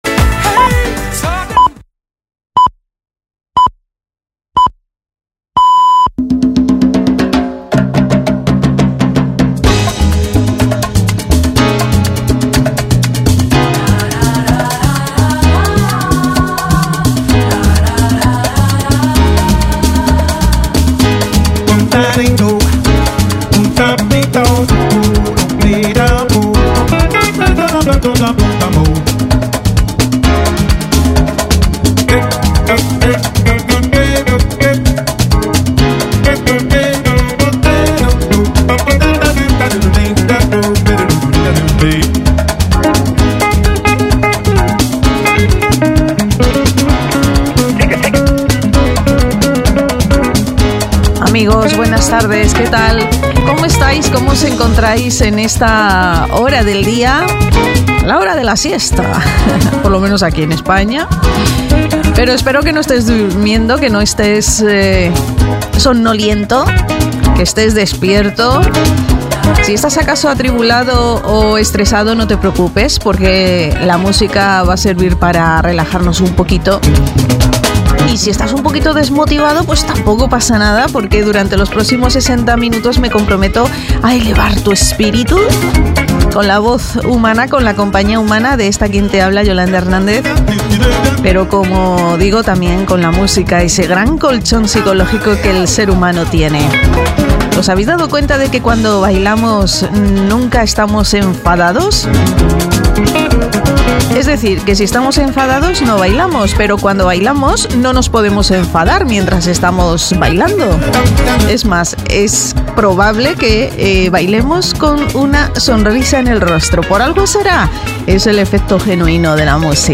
Todos los éxitos de los 80s, 90s y 00s que forman parte de tu vida suenan en "Recuerdas".